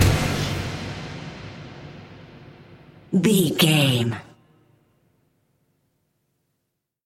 Atonal
synthesiser
percussion
ominous
dark
suspense
haunting
tense
creepy
spooky